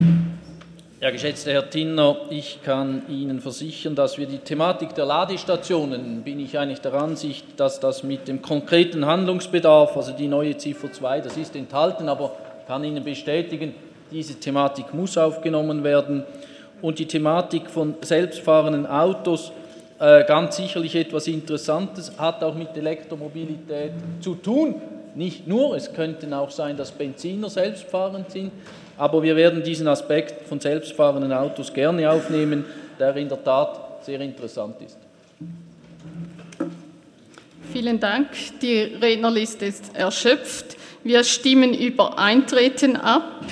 27.11.2017Wortmeldung
Session des Kantonsrates vom 27. und 28. November 2017